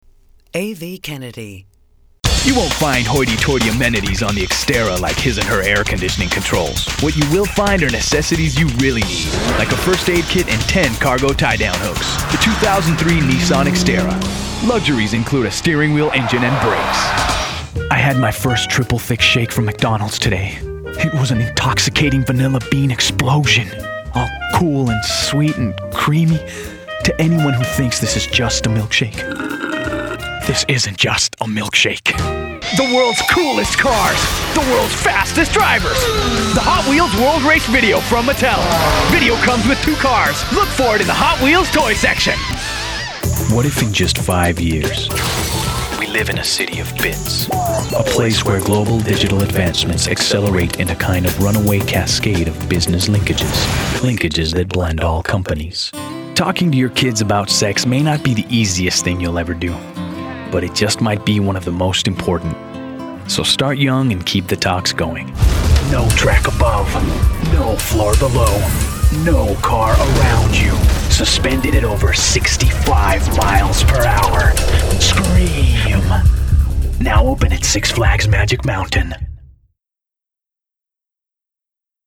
Commerical